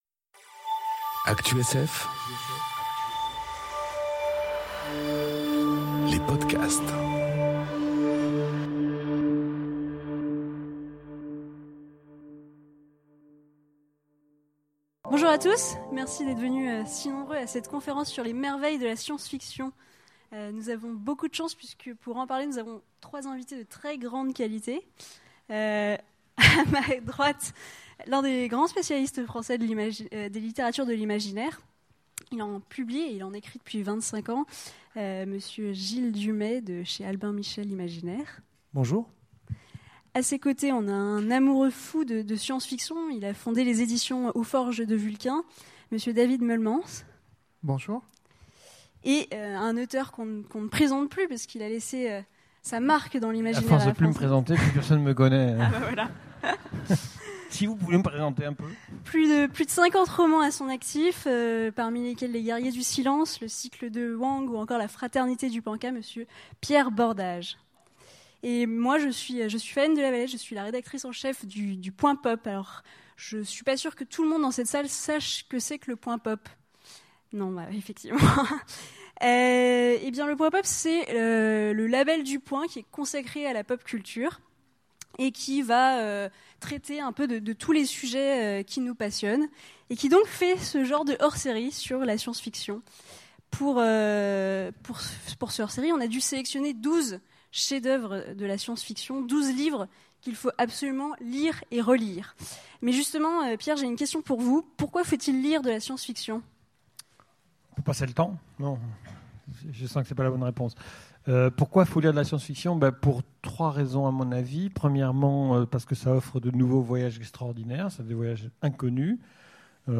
Conférence Hors-série Le Point Pop : Les 12 merveilles de la sf enregistrée aux Utopiales 2018